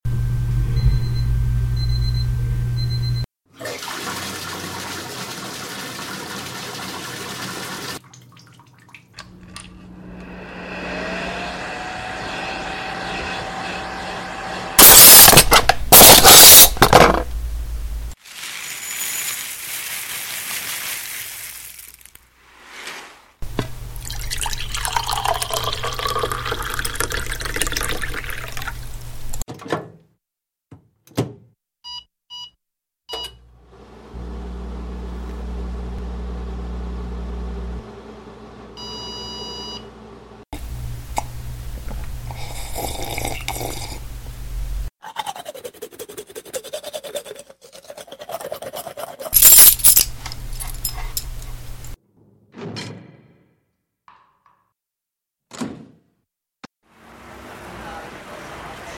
Muntatge sonor: ¡Bon dia!